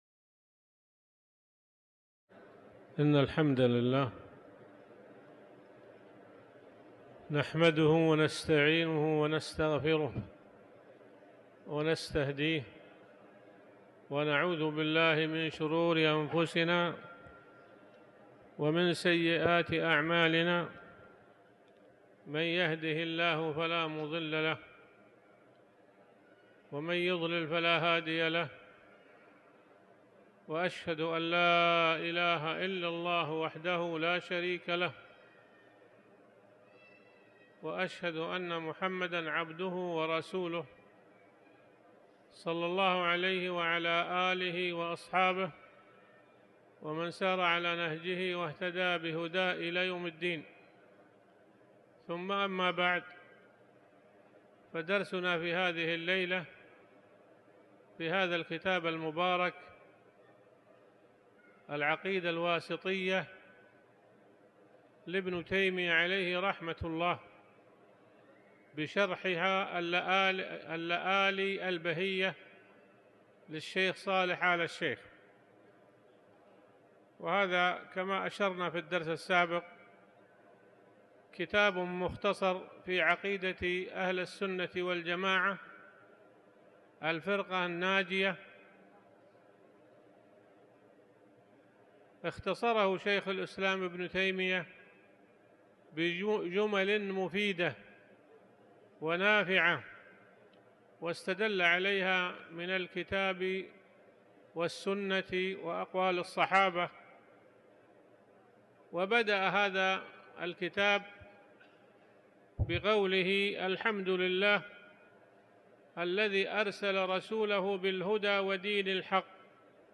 تاريخ النشر ١٢ رجب ١٤٤٠ هـ المكان: المسجد الحرام الشيخ